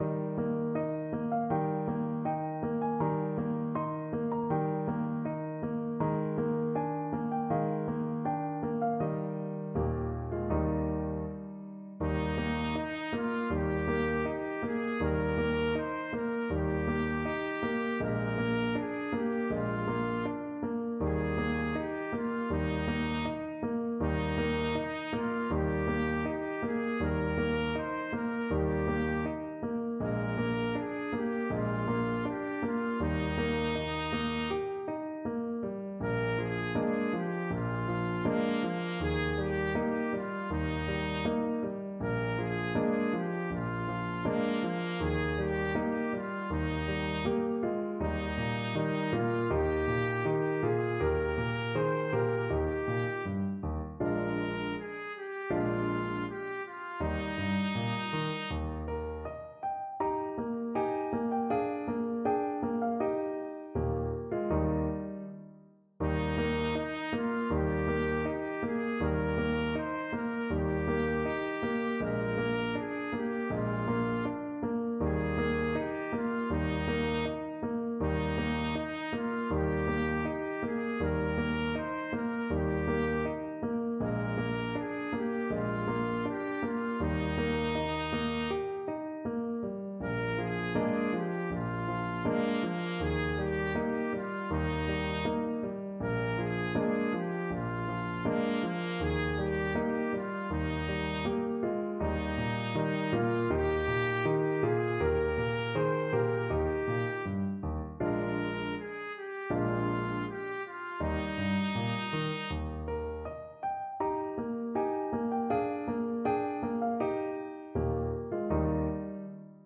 Andante
4/4 (View more 4/4 Music)